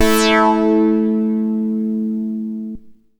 57-MASS LEAD.wav